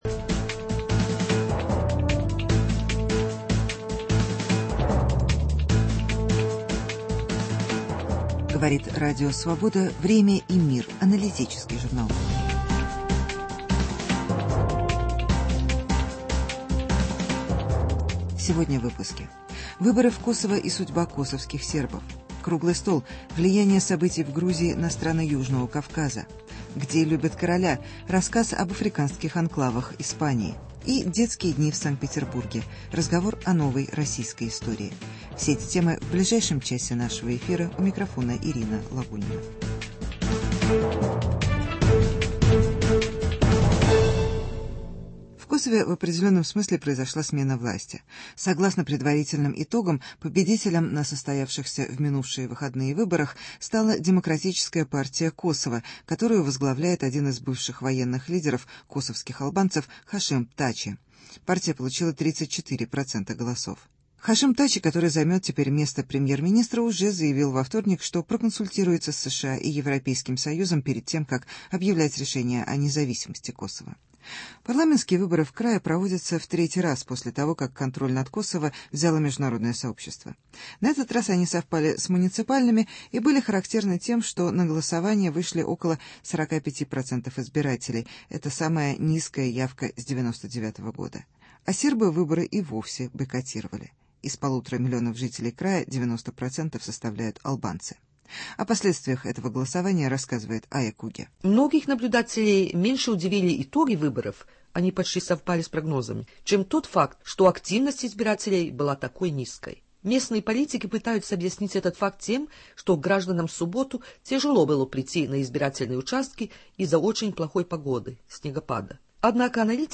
Выборы в Косово и судьба косовских сербов. Круглый стол: Влияние событий в Грузии на страны Южного Кавказа.